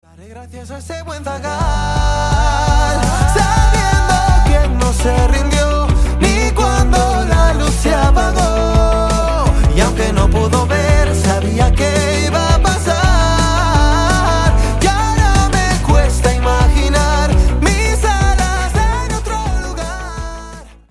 de la categoría Pop